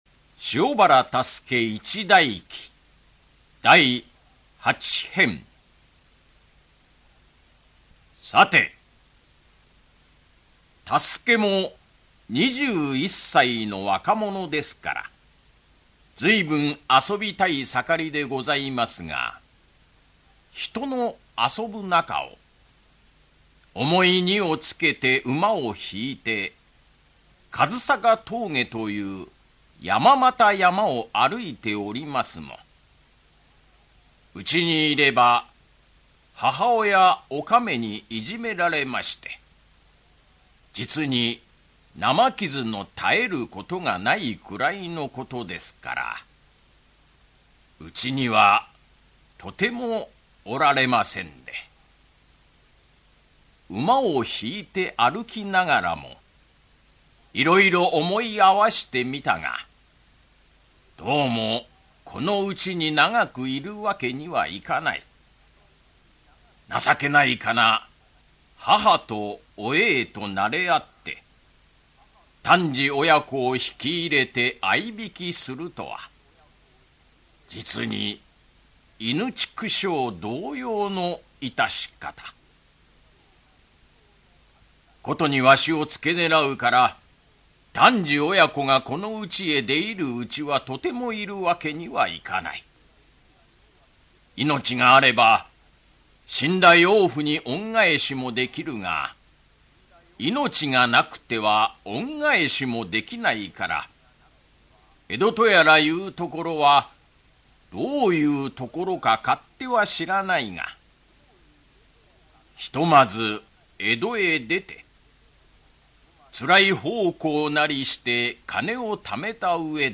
[オーディオブック] 塩原多助一代記-第八・九編-
口演：